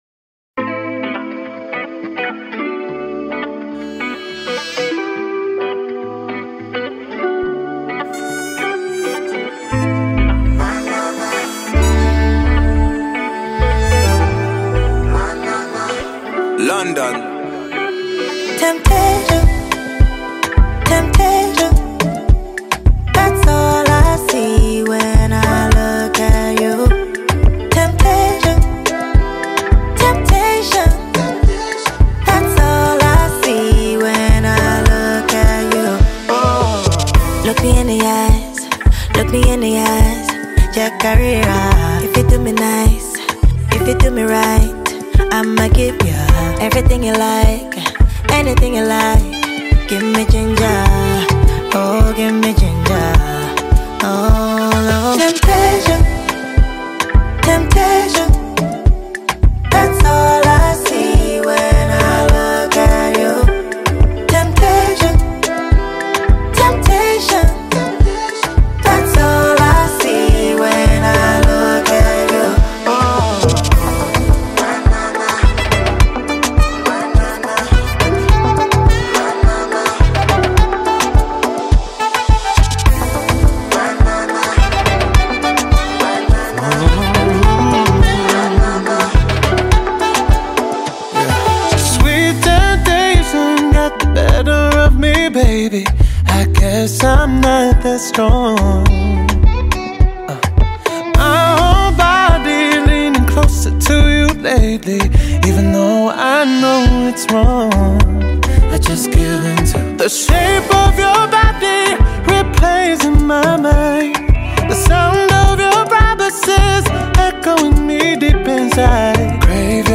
cool rhythm